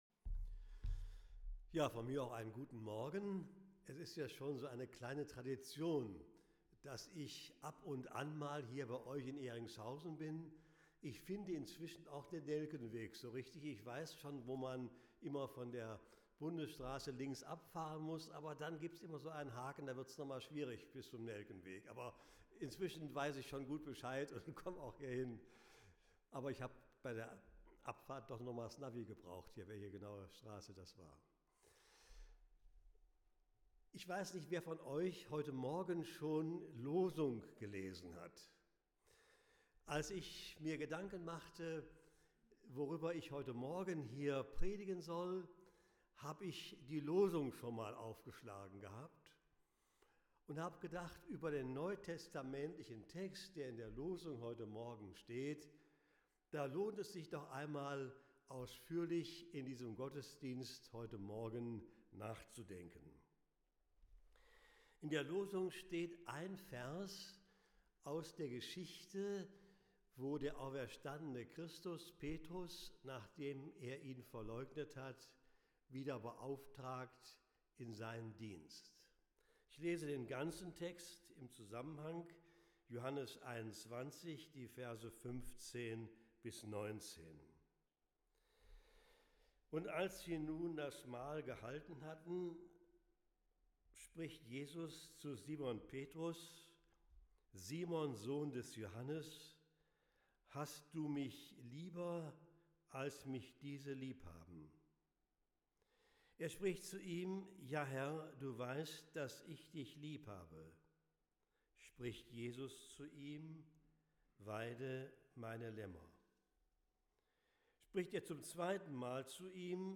Gottesdienst zu Trinitatis